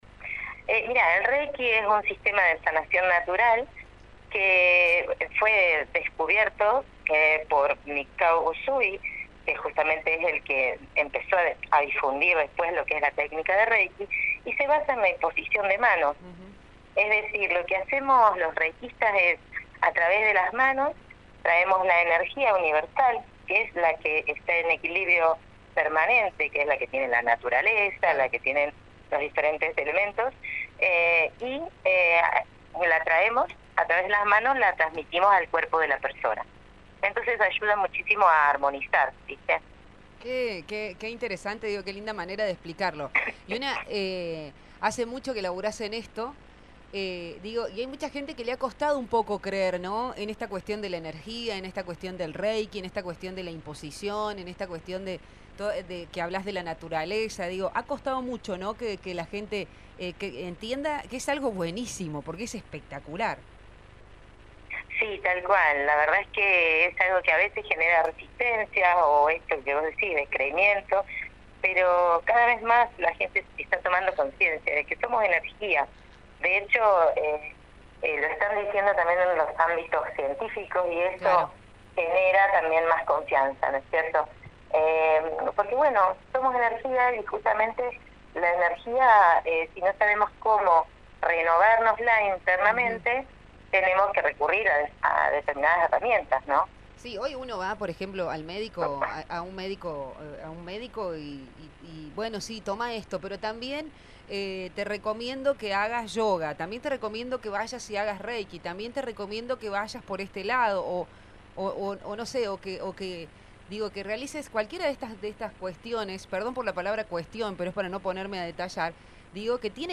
Terapeuta Holística, habló con nosotros en el «Día Mundial del Reiki». Qué es el Reiki, cómo las personas se acercan cada vez más a estas terapias, qué pasa cuando te haces reiki.